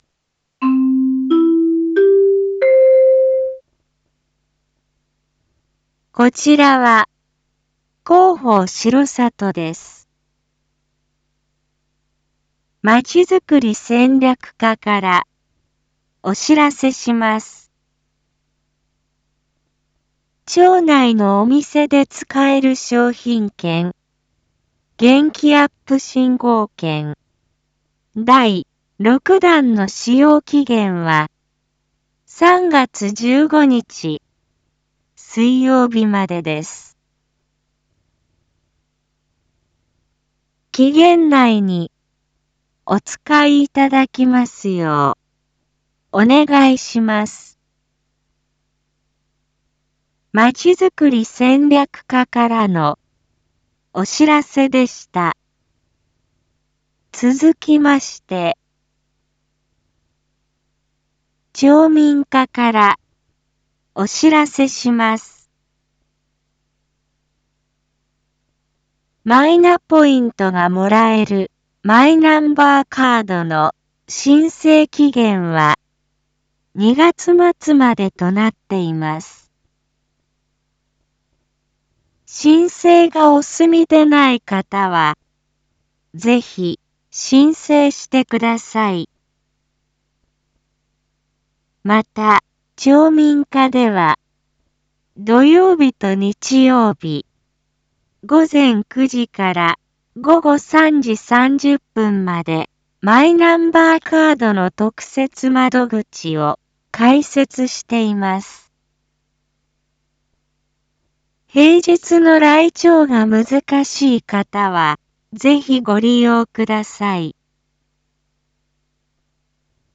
一般放送情報
Back Home 一般放送情報 音声放送 再生 一般放送情報 登録日時：2023-02-25 19:02:22 タイトル：R5.2.25 19時放送分 インフォメーション：こちらは、広報しろさとです。